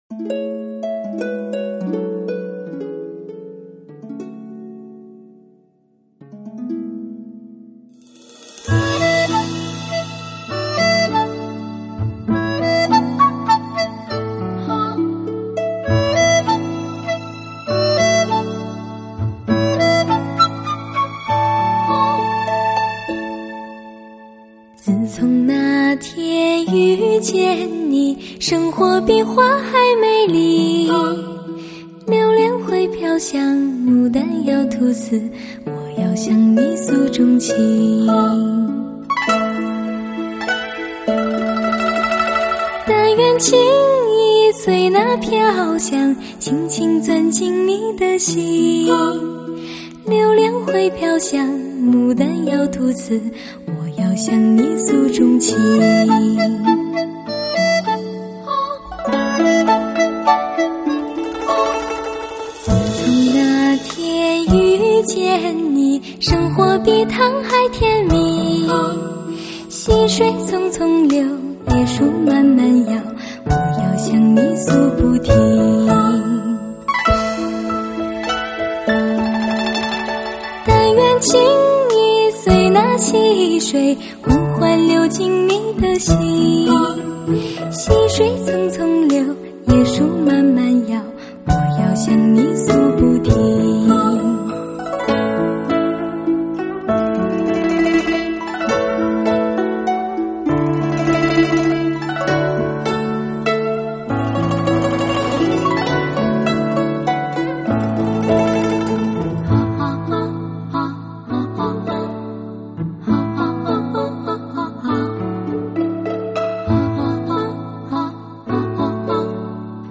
轻盈、清新、动听。
她的嗓音细腻无暇，
充满了磁性，却不失纯真。